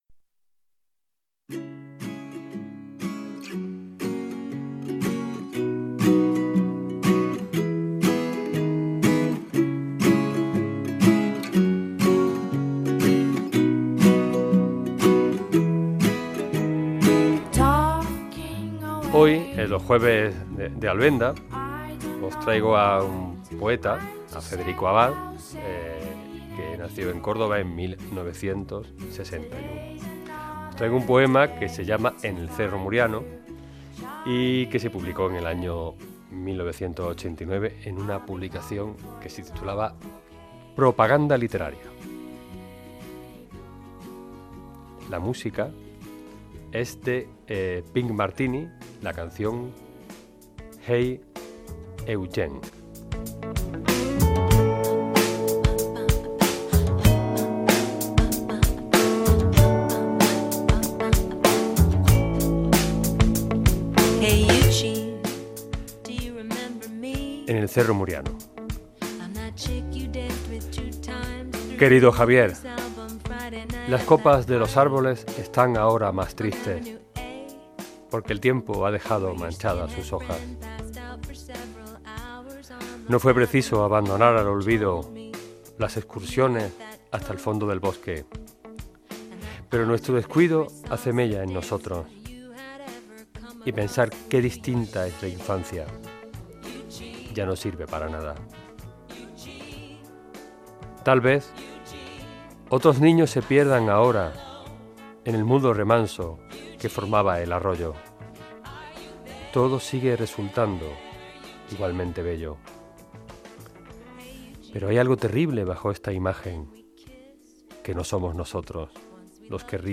Radio Córdoba, Cadena SER